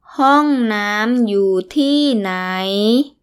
∧ hoorng / naam _ yuu ∧ tii ∨ naii